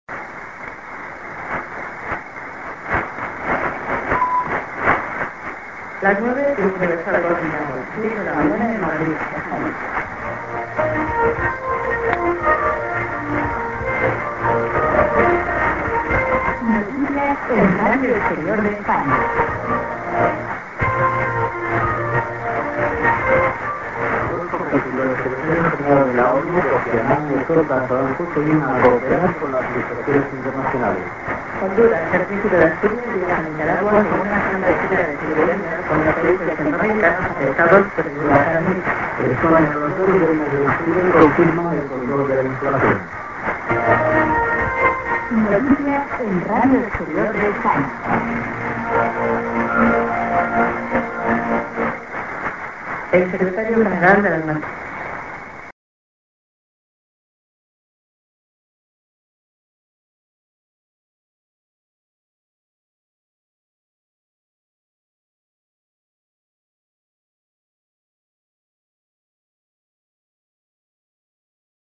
b:　TS->ID(women)->IS->ID(women)